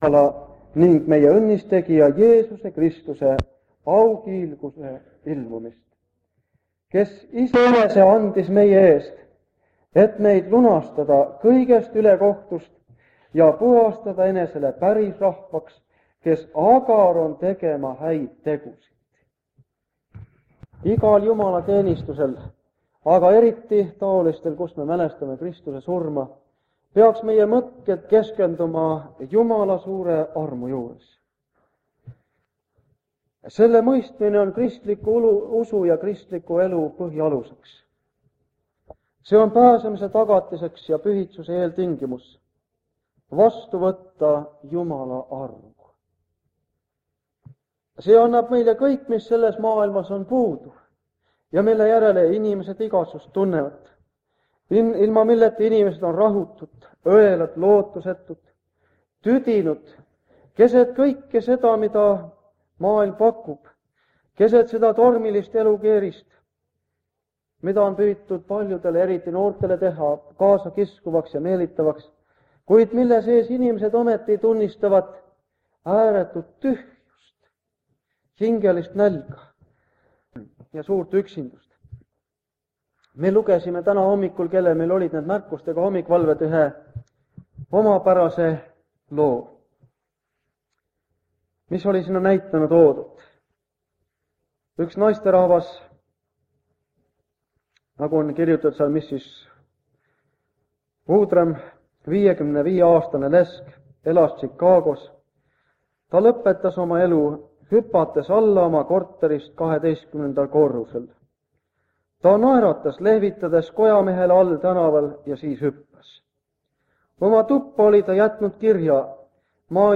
Jutlus vanalt lintmaki lindilt aastast 1980.